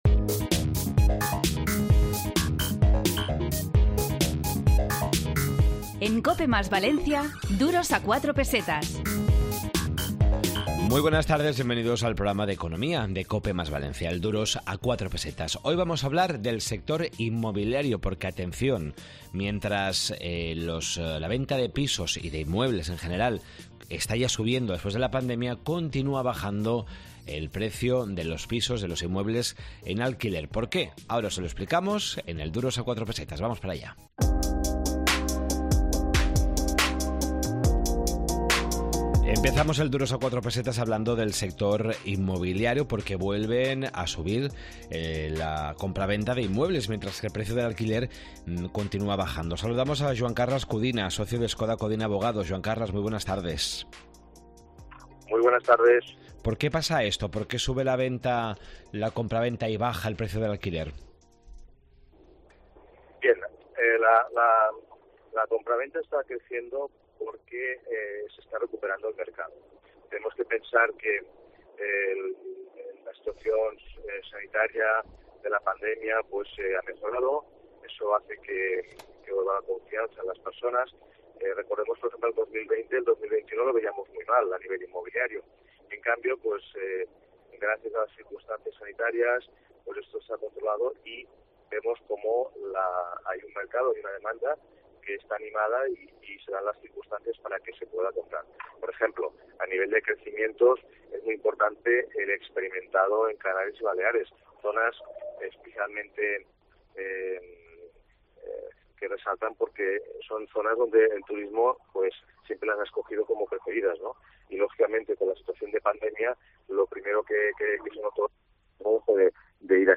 Esta semana en Duros a 4 Pesetas de COPE + Valencia, en el 92.0 de la FM, hemos hablado sobre la caída de los precios del alquiler y el incremento de los precios de la vivienda, la geolocalización de personas y grupos, y otras claves económicas de la semana.